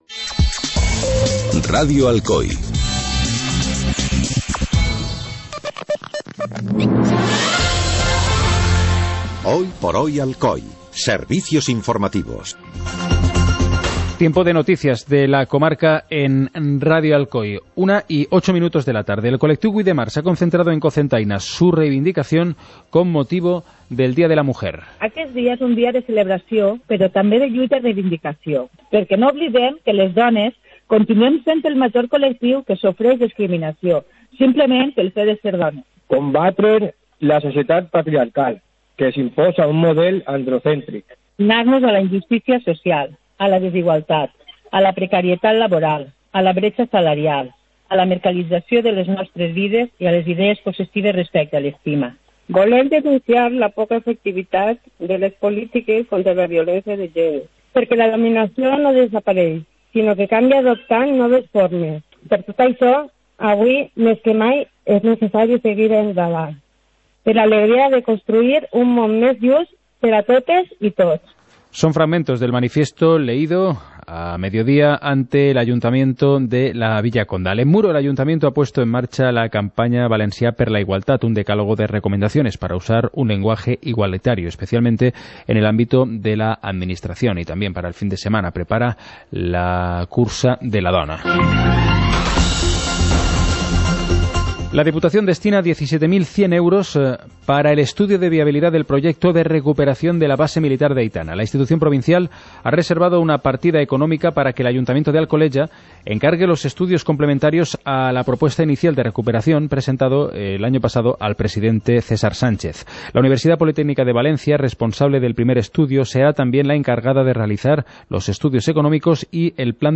Informativo comarcal - miércoles, 08 de marzo de 2017